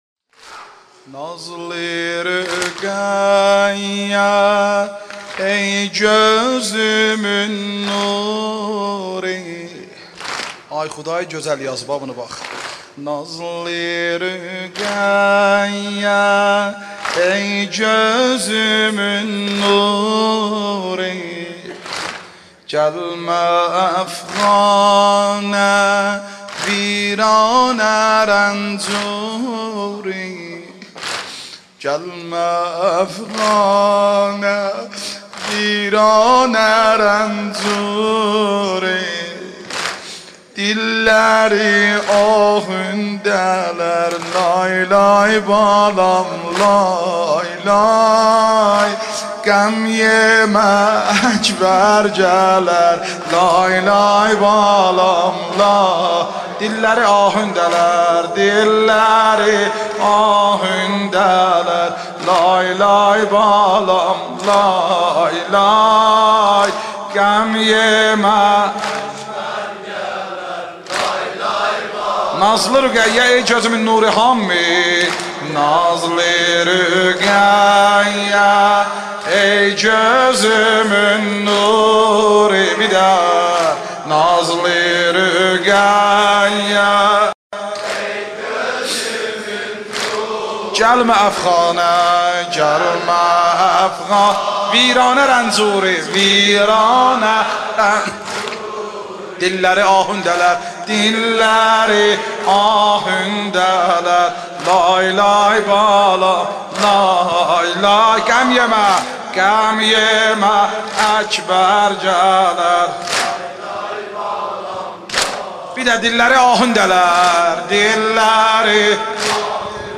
نوحه ترکی